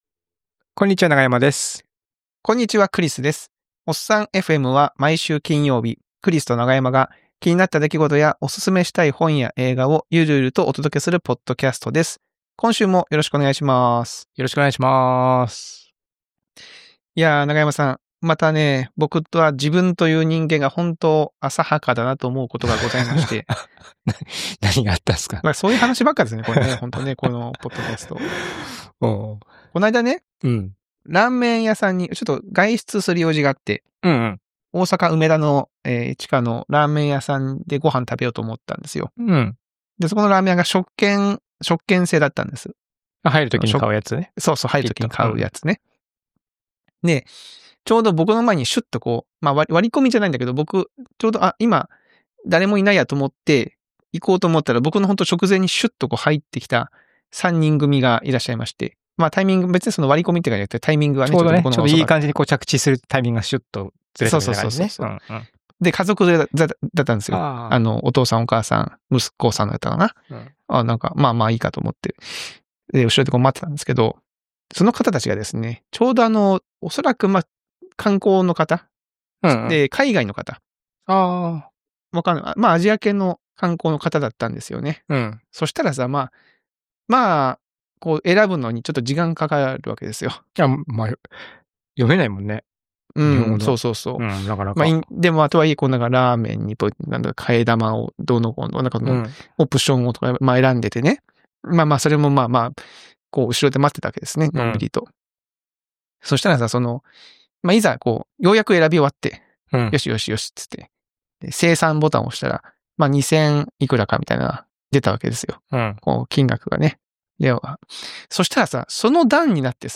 ラーメン屋にて